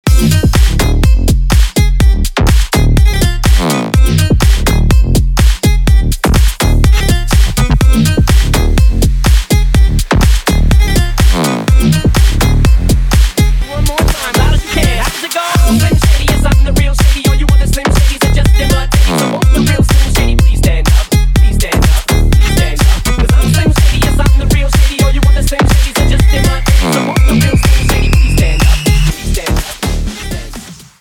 • Качество: 320 kbps, Stereo
Ремикс
ритмичные